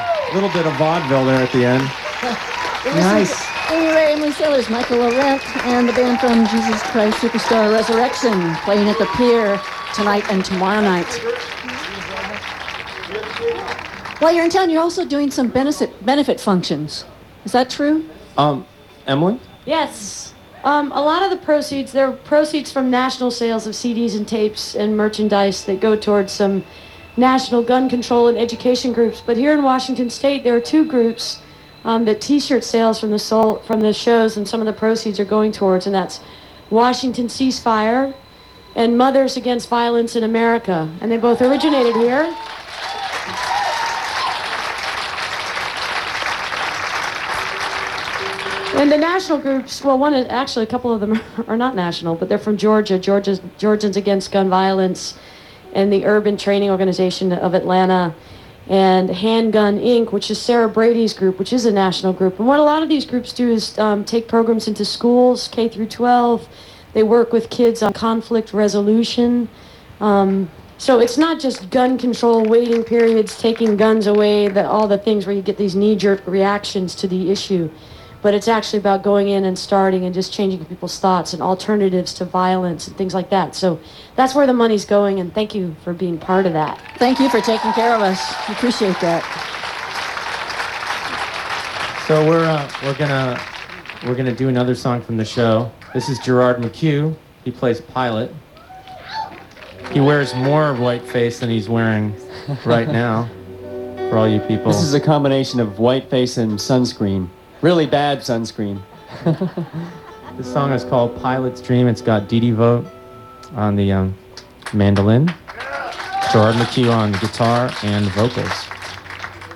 03. interview (1:59)